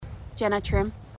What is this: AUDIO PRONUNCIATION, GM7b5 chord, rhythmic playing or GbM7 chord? AUDIO PRONUNCIATION